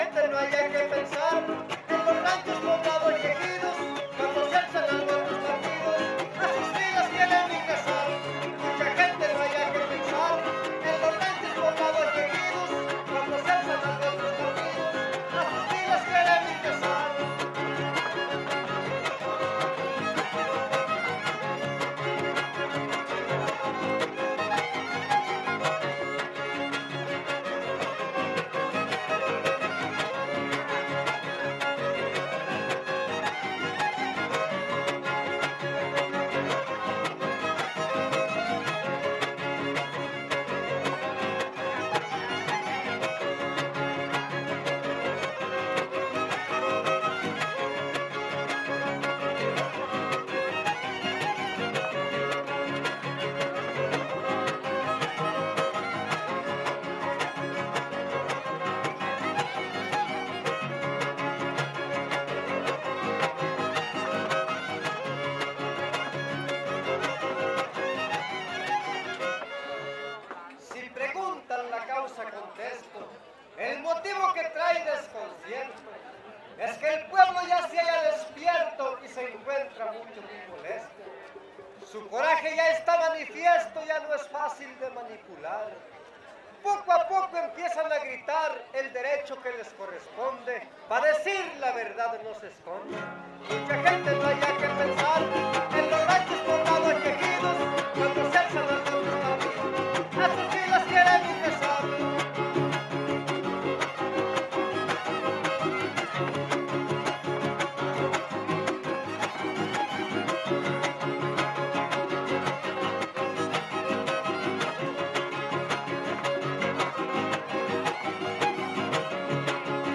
Poesía popular Huapango arribeño
Violín Vihuela Guitarra
Topada ejidal: Cárdenas, San Luis Potosí